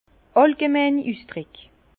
Ville Prononciation 67 Herrlisheim